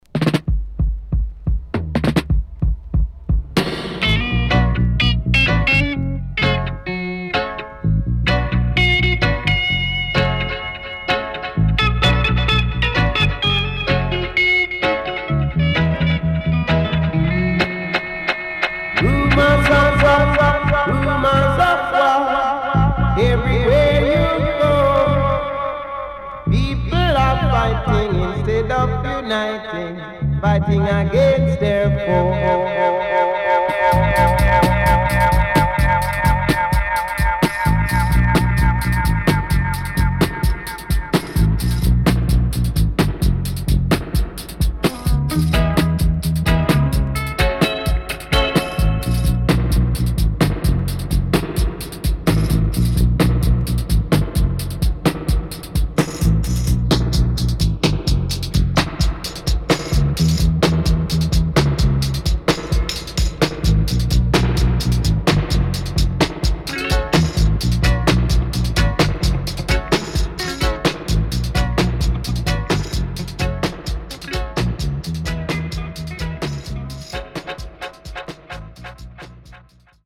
Killer Stepper Roots.後半Deejay接続。B:Dubwise